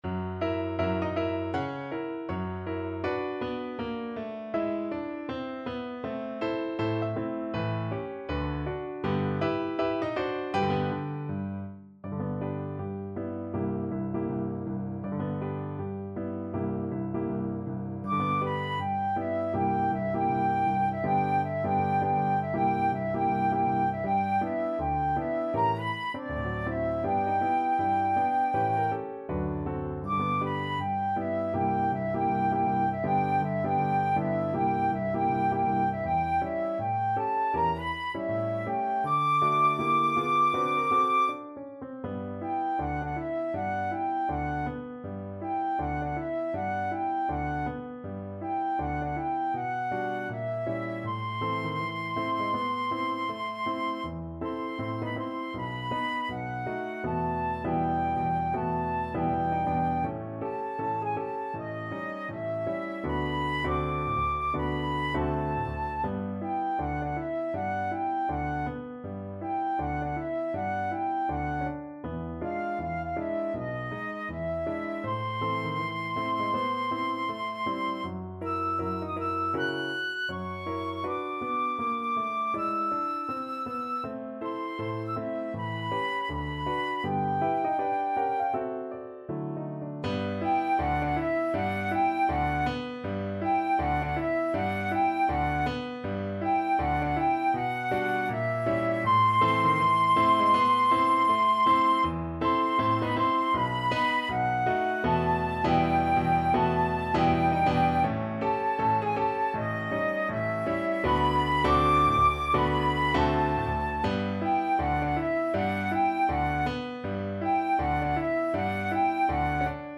~ = 160 Moderato
2/2 (View more 2/2 Music)
Jazz (View more Jazz Flute Music)